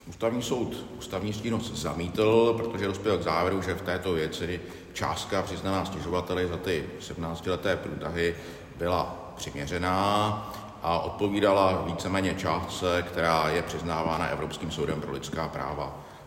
Důvod zamítnutí stížnosti vysvětluje soudce zpravodaj Zdeněk Kühn.